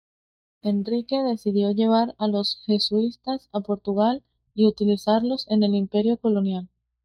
co‧lo‧nial
/koloˈnjal/